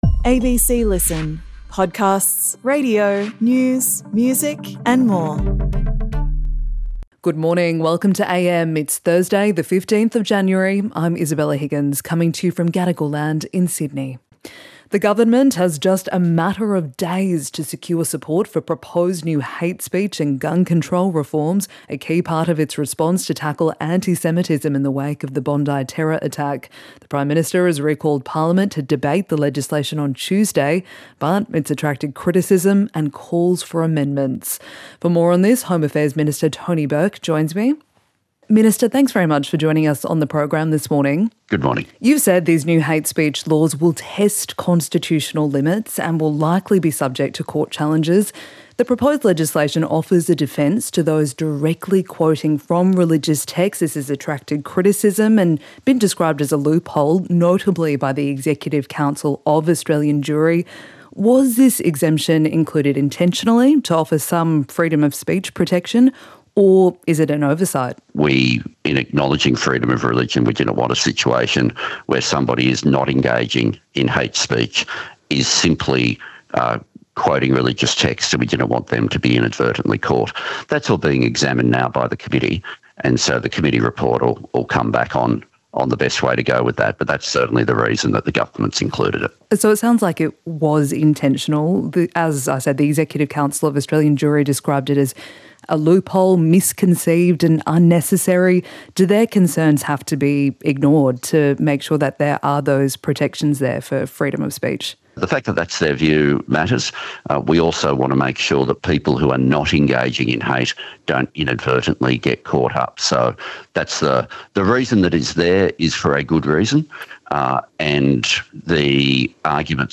AM is Australia's most informative morning current affairs program. With key political interviews and stories about the Australian way of life, AM sets the agenda for the nation’s daily news and current affairs coverage.